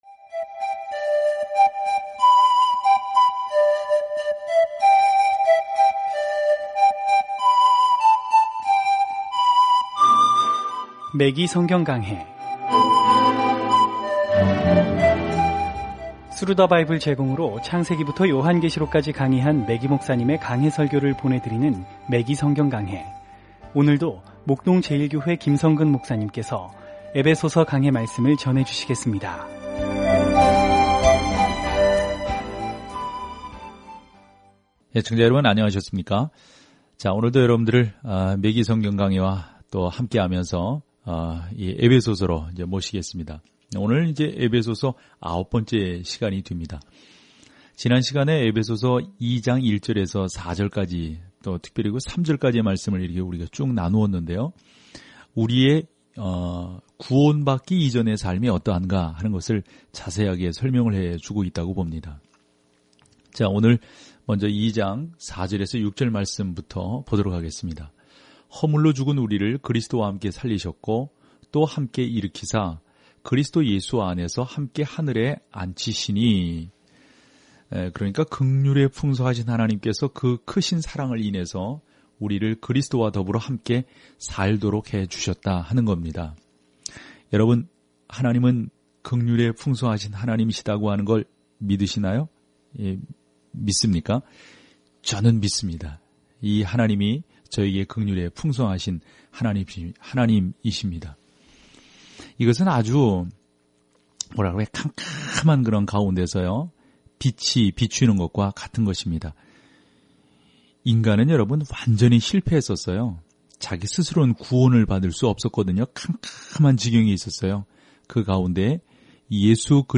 오디오 공부를 듣고 하나님의 말씀에서 선택한 구절을 읽으면서 매일 에베소서를 여행하세요.